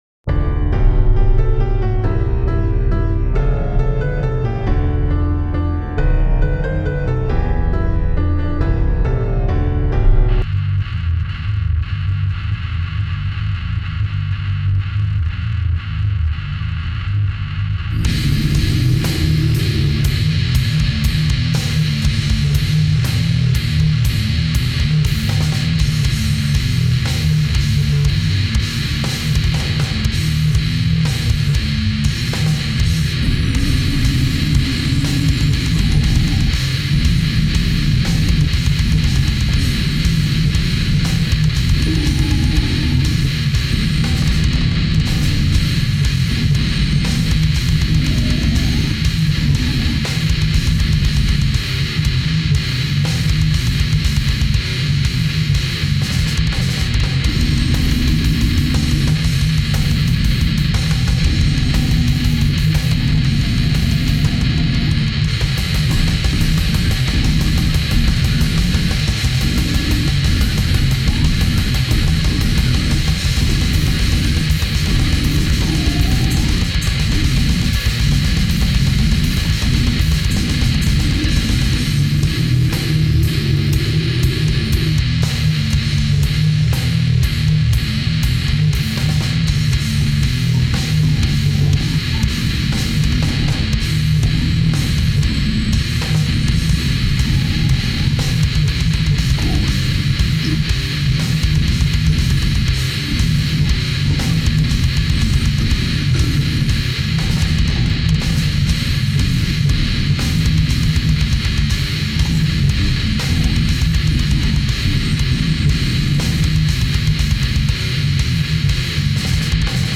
pure brutal death sounds suitable for christmas